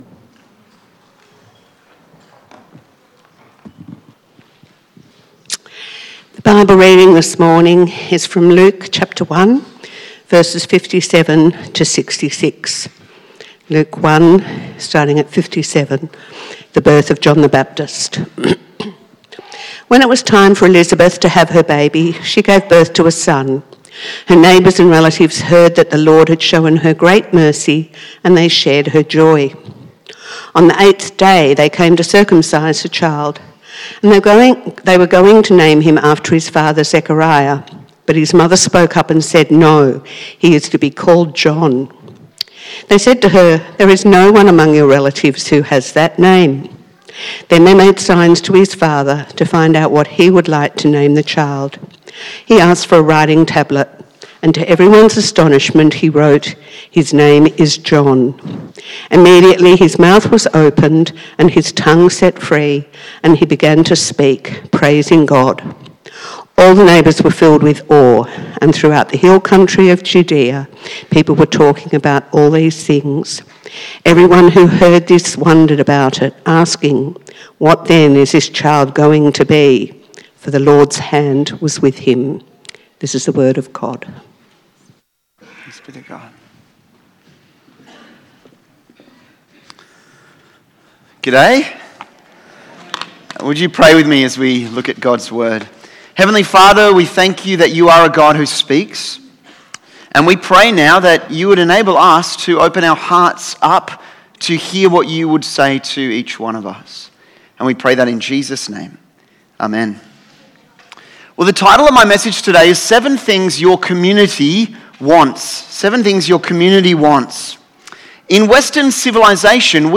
Luke 1:57-66 Service Type: AM   What do the Scriptures say about community?